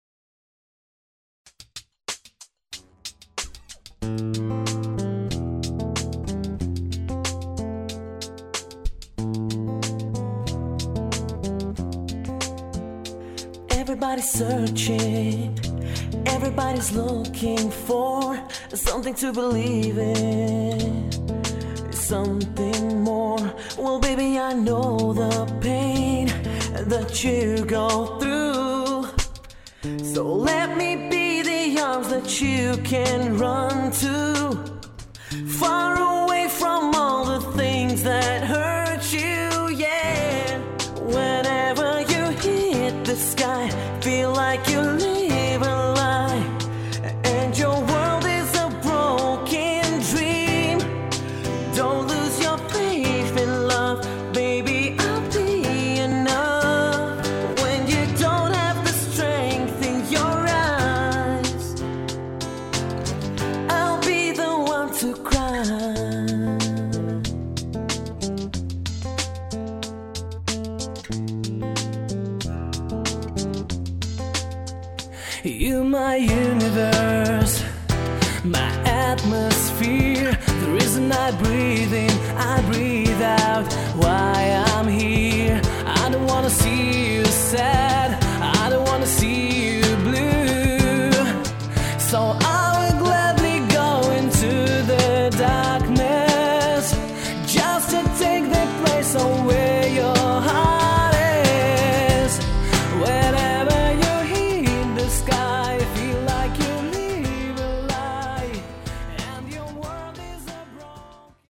(Студийная запись)